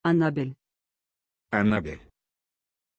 Pronuncia di Annabel
pronunciation-annabel-ru.mp3